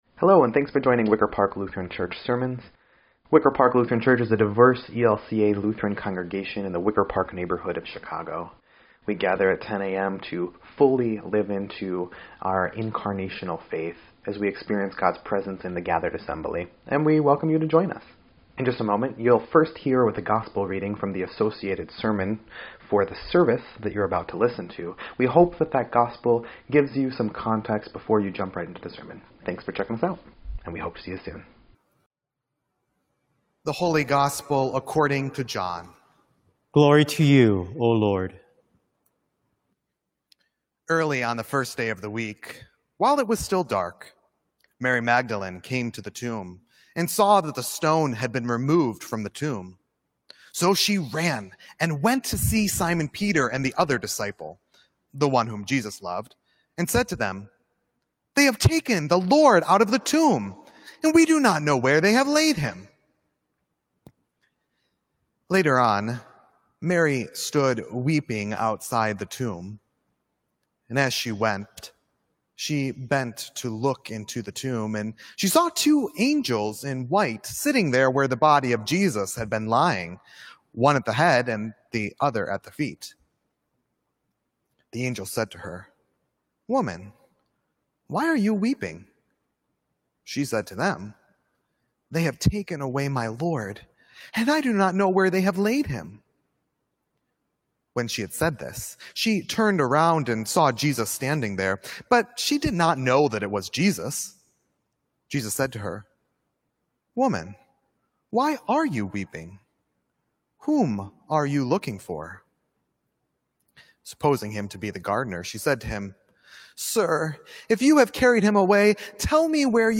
7.26.20_Sermon_EDIT.mp3